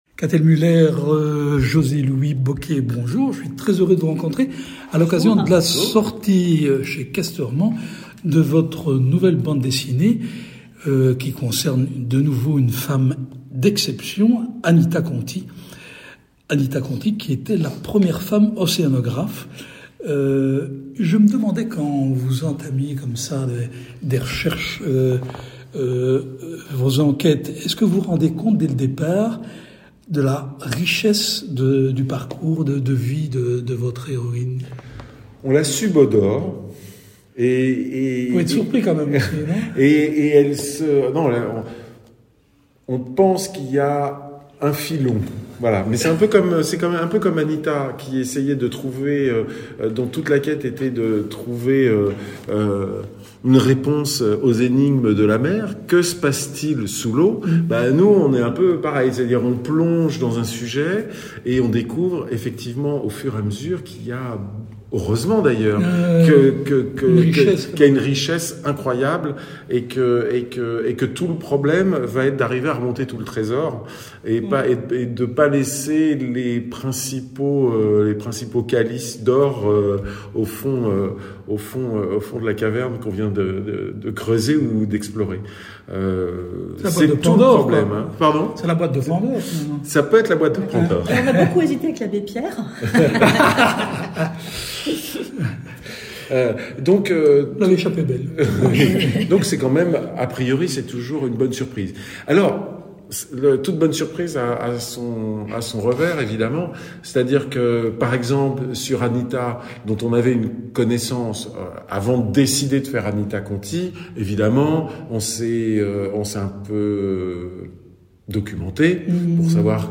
Rencontre.